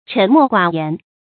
注音：ㄔㄣˊ ㄇㄛˋ ㄍㄨㄚˇ ㄧㄢˊ
讀音讀法：
沉默寡言的讀法